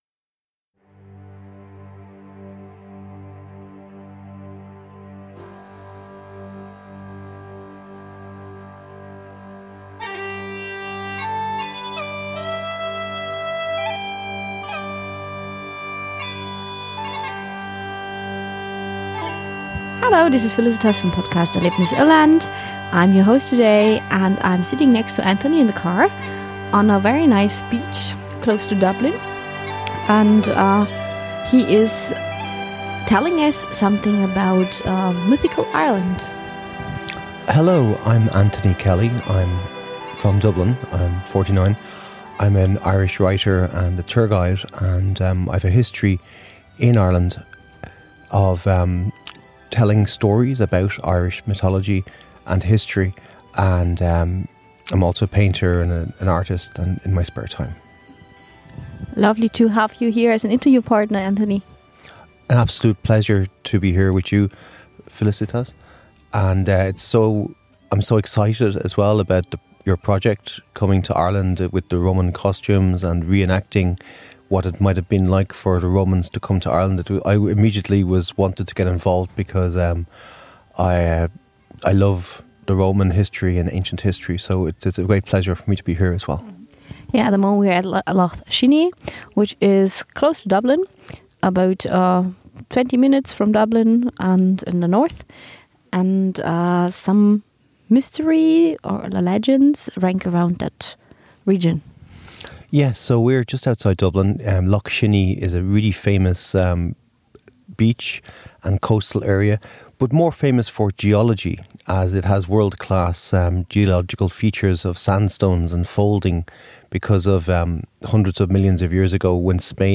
Ein Gespräch voller Humor, Tiefe und magischer Bilder, das zeigt, wie Irlands Geschichten in der Landschaft, den Menschen – und vielleicht auch in dir selbst weiterleben.